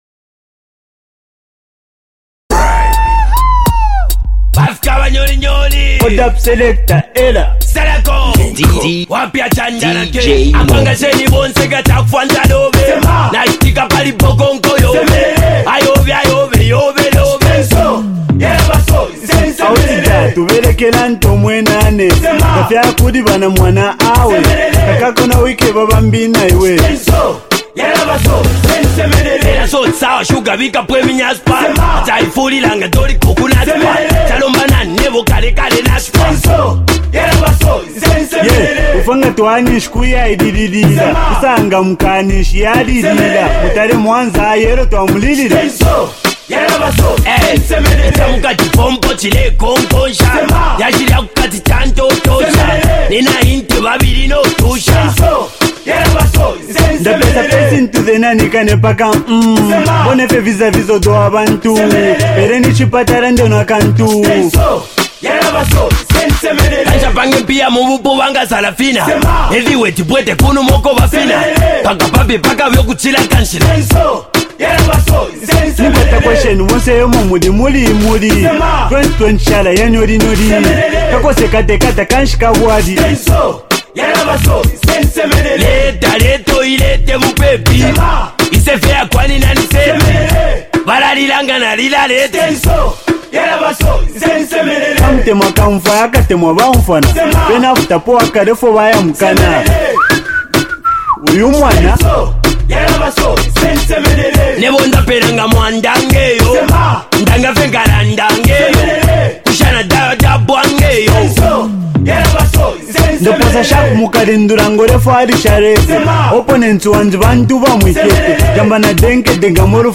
blending street vibes and energetic flows for their fans.
the celebrated Zambian duo